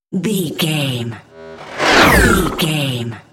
Whoosh electronic shot
Sound Effects
Atonal
futuristic
high tech
intense